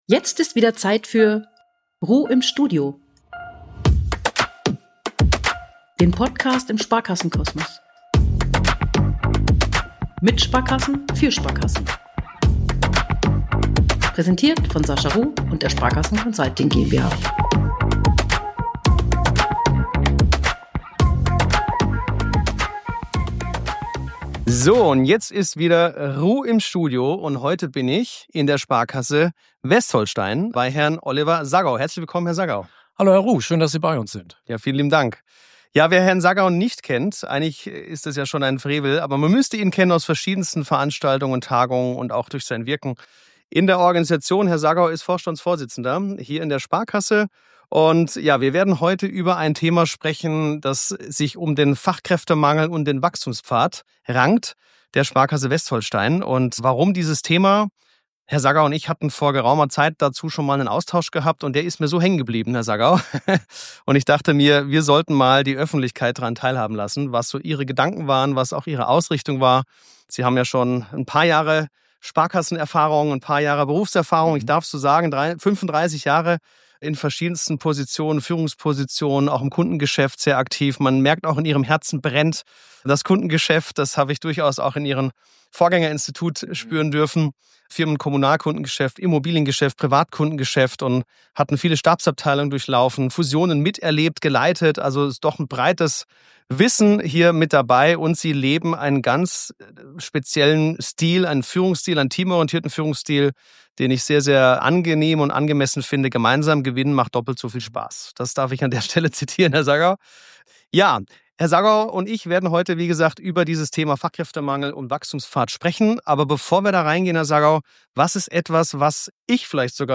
Freut euch auf ein Gespräch über Recruiting, Retention, regionale Verantwortung – und die Kraft eines klaren Plans.